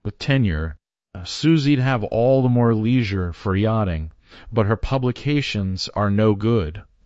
text-to-speech
This zero-shot text-to-speech operation efficiently processes written content and produces high-quality audio outputs that sound natural and engaging.